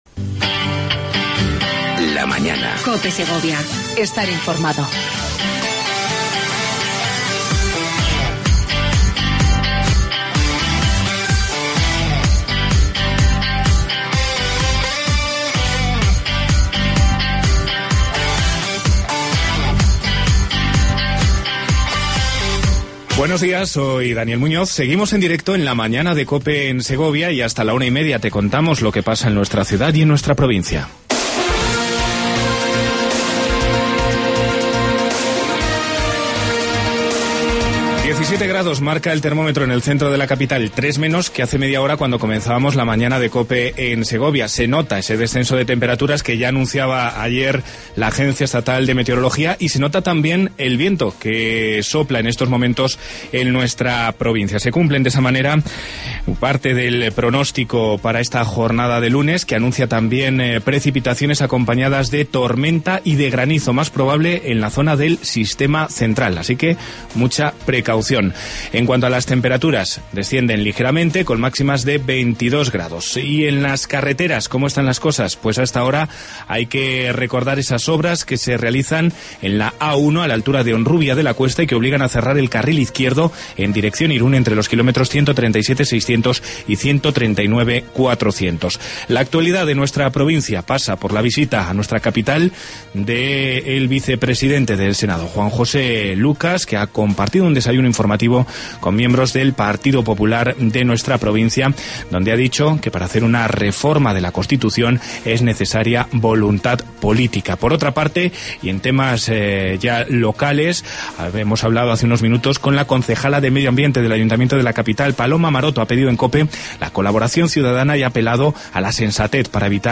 AUDIO: Entrevista a Javier Lopez Escobar, delegado territorial de la Junta de Castilla Y León en Segovia.